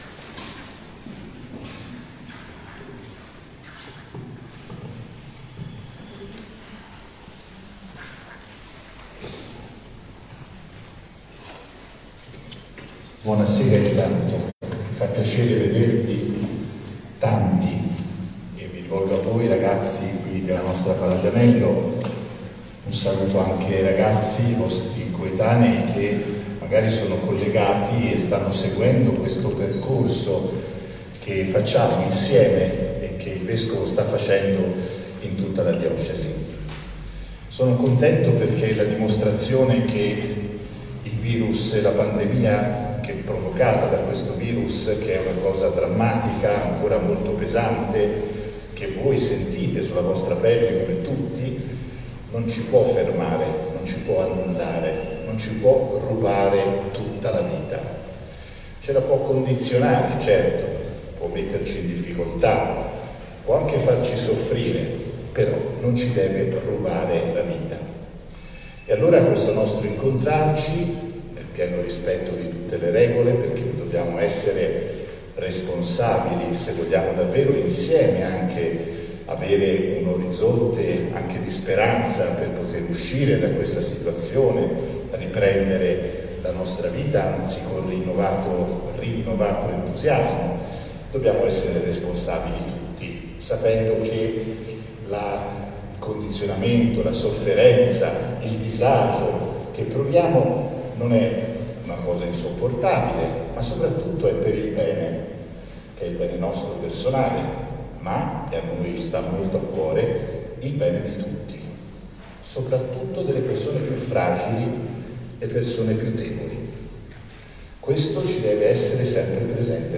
L'intervento tenuto da Mons. Claudio Maniago nella quarta tappa del percorso quaresimale a cura della Pastorale Giovanile.
Intervento-del-Vescovo-a-Palagianello-4-Marzo-2021.mp3